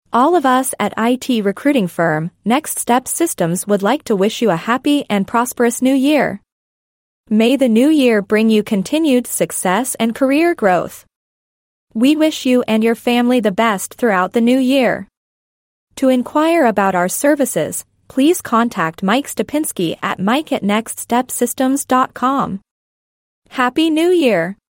A Happy New Year Message from IT Recruiting Firm, Next Step Systems Using Artificial Intelligence (AI)
Please take a moment to listen to a Happy New Year audio message from our IT recruiting firm generated by Artificial Intelligence (AI). Next Step Systems wishes you a happy and prosperous New Year filled with continued success and career growth.